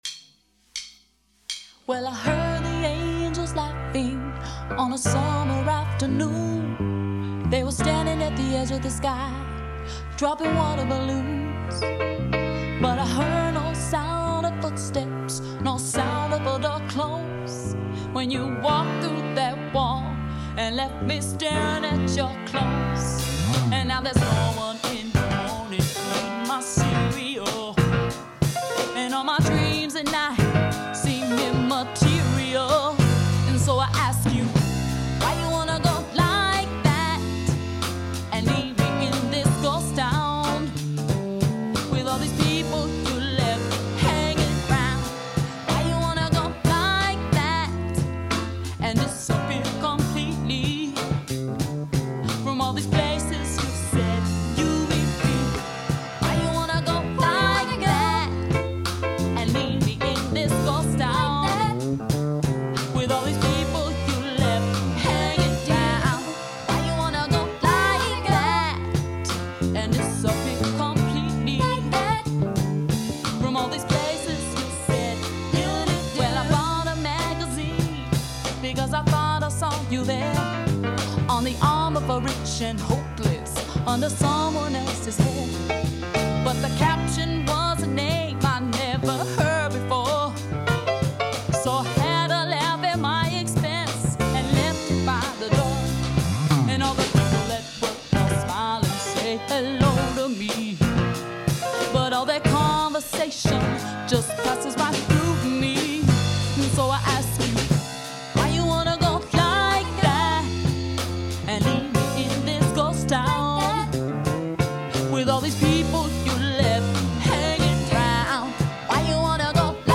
(original cast 4-track version)